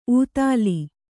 ♪ ūtāli